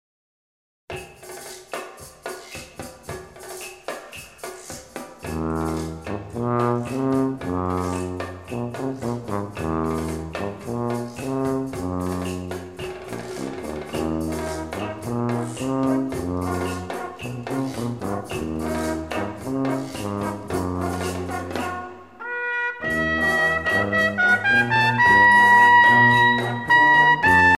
danse : rumba
circonstance : militaire